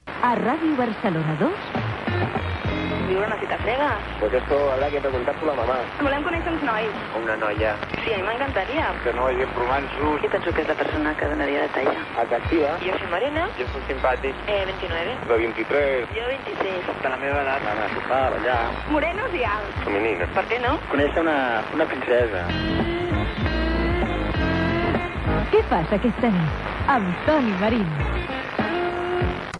Promoció del programa
Gravació realitzada a València.